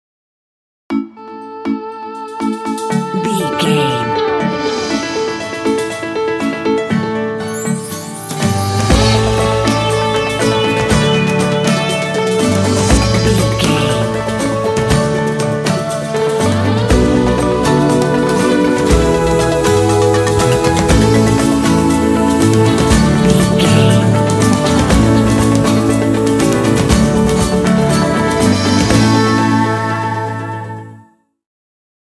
This track is great for calm and fun exploration.
Ionian/Major
bouncy
happy
groovy
bright
motivational
percussion
drums
piano
synthesiser
pop
rock
contemporary underscore